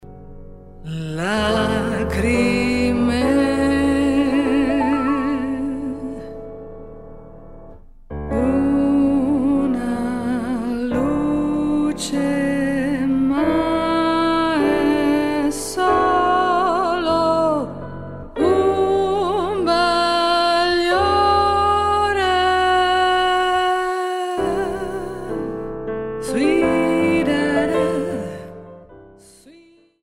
for female voice and two pianists
a Steinway mod.D 274 piano.